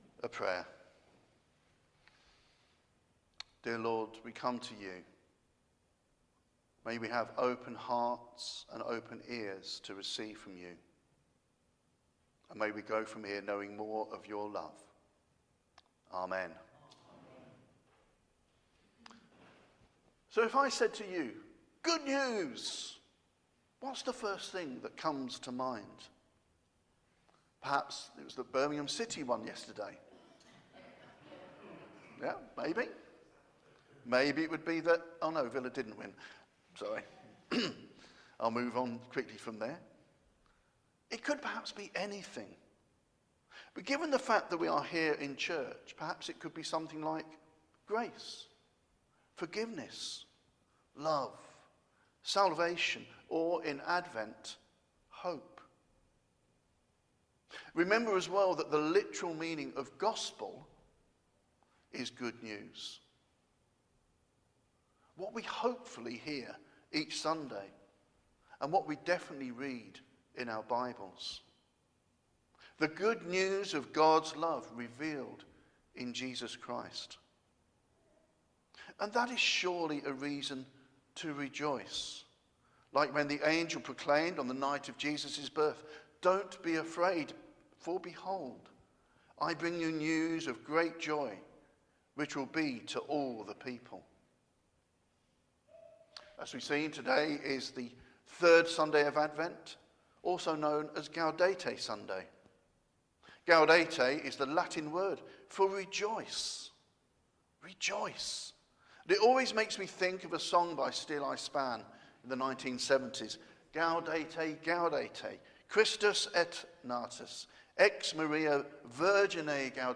Media for Holy Communion on Sun 15th Dec 2024 09:00 Speaker
Theme: John the Baptist's message Sermon Search